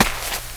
Index of /90_sSampleCDs/AKAI S6000 CD-ROM - Volume 6/Human/FOOTSTEPS_2
DIRT 1.WAV